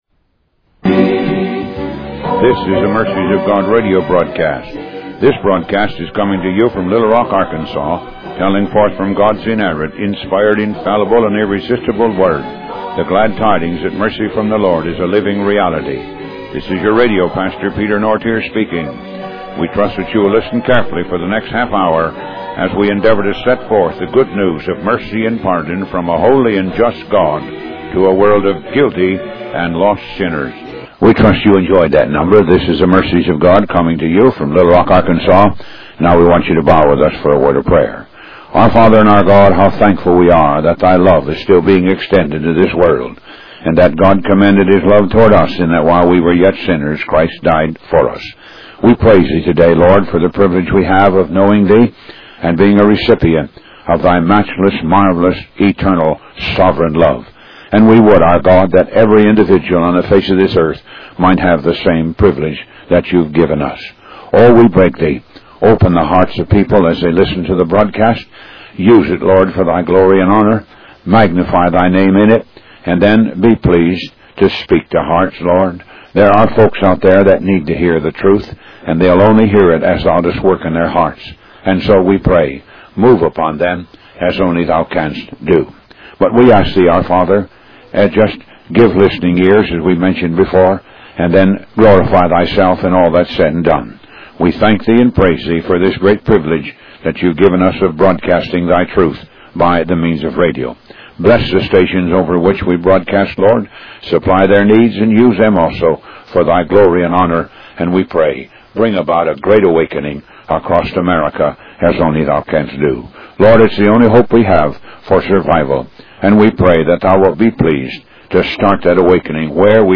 Talk Show Episode, Audio Podcast, Moga - Mercies of God Association and Sovereign Anger of the Lord on , show guests , about Sovereign Anger of the Lord, categorized as Health & Lifestyle,History,Love & Relationships,Philosophy,Psychology,Christianity,Inspirational,Motivational,Society and Culture